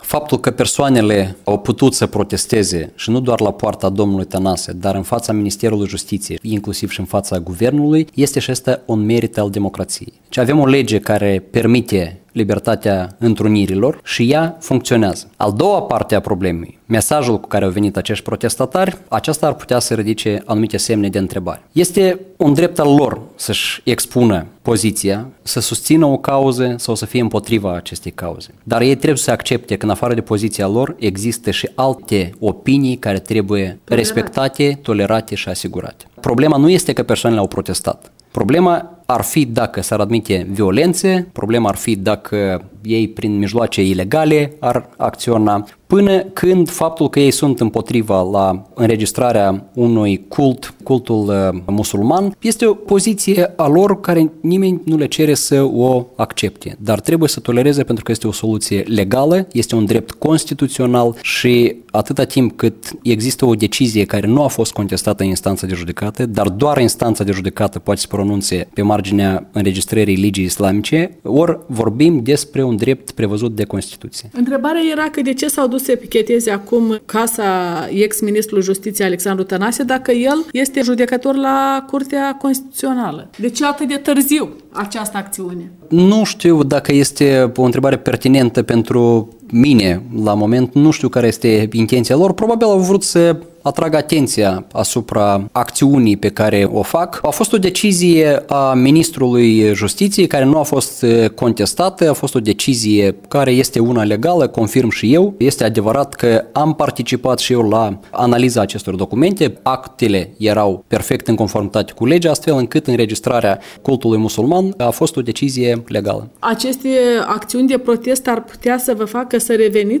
Ministrul Justiţiei Oleg Efrim răspunde întrebărilor